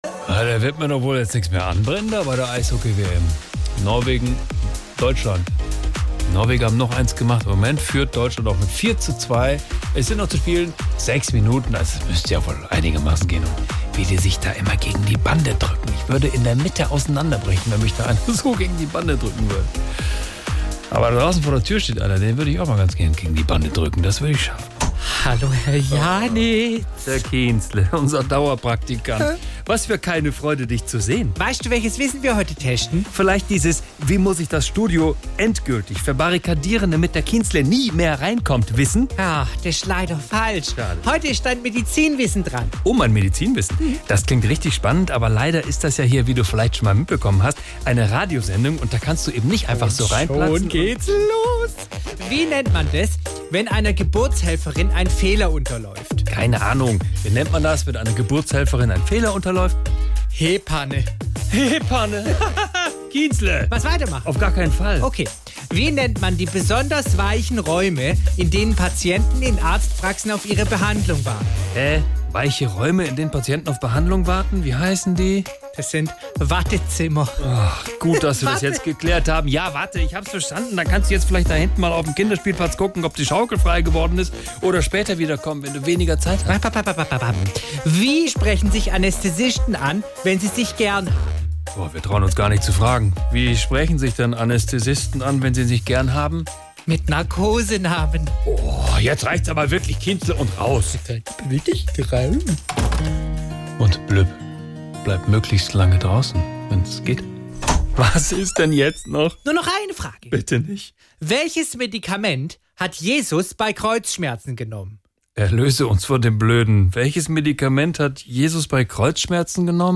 SWR3 Comedy Kienzle und das Medizinwissen